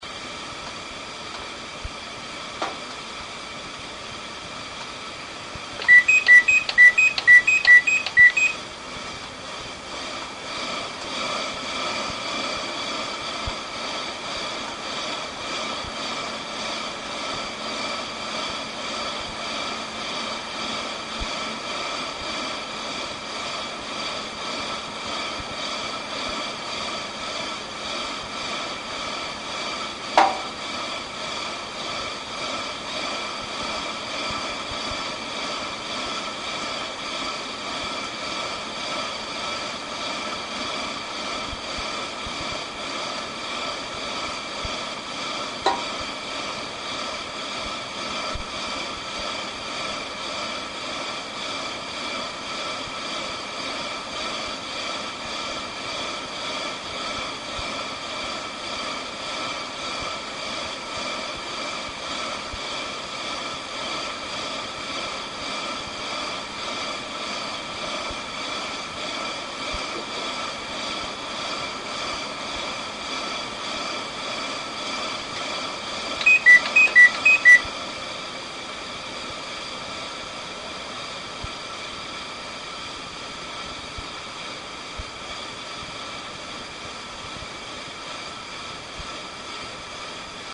再点火後、設定温度を20℃→24℃→20℃に戻すバーナーの音
別のコンデンサーマイクロホン（アルパインのカーナビのマイク）に取り替 えて録音したので、ちょっと音質が違うかも。